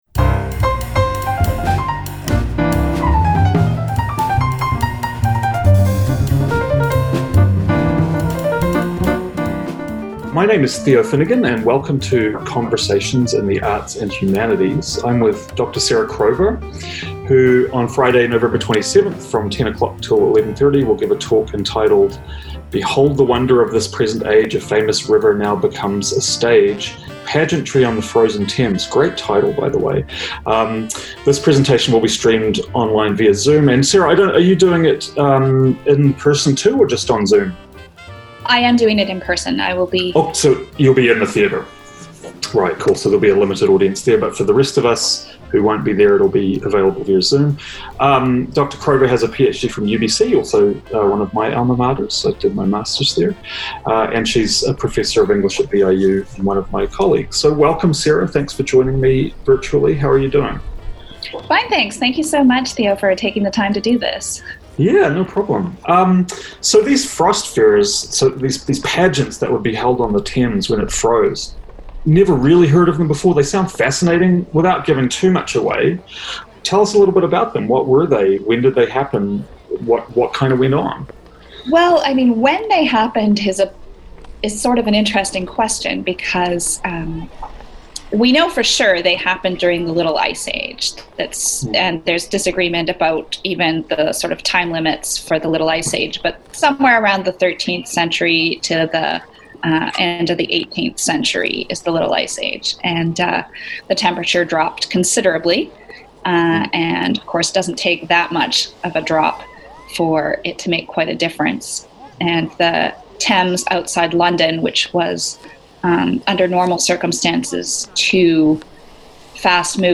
This episode includes a short excerpt from Series 10 of Doctor Who.